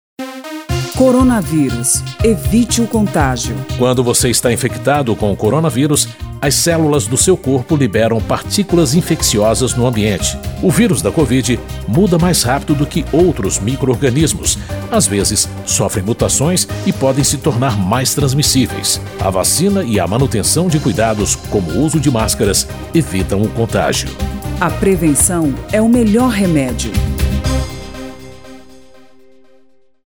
spot-vacina-coronavirus-5-1.mp3